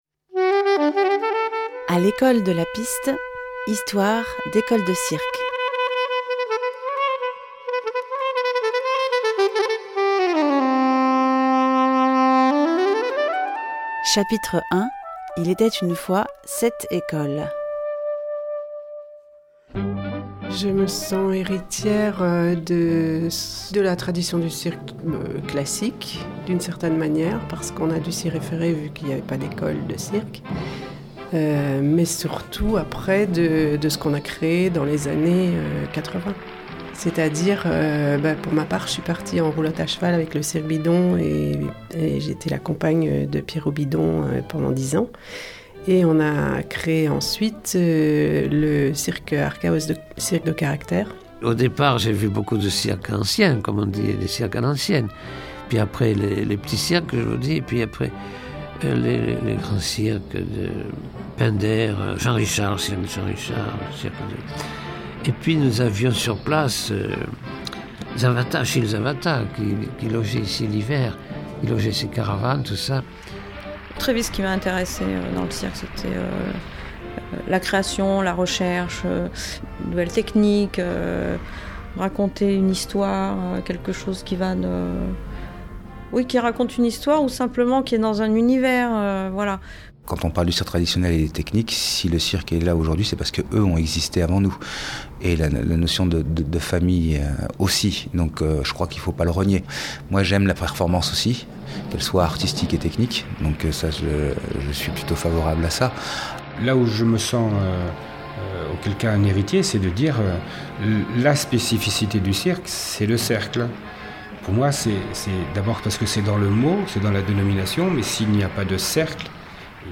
Un documentaire sonore